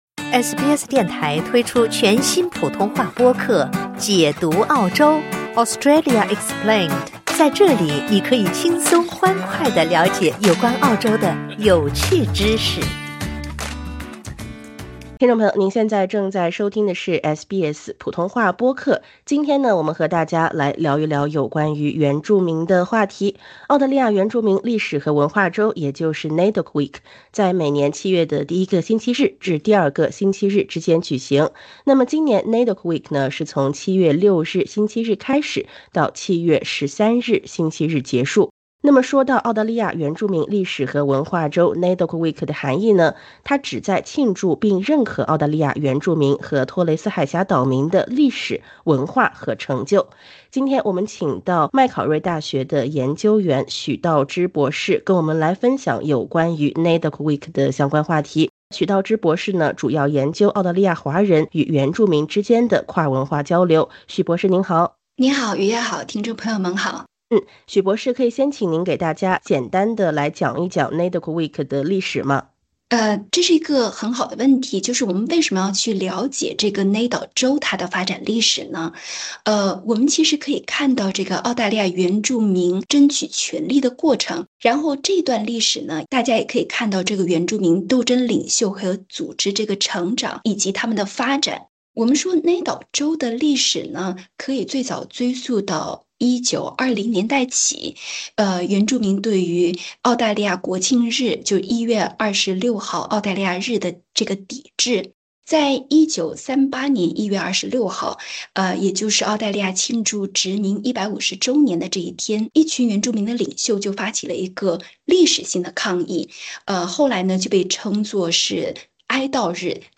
点击音频，收听学者分享。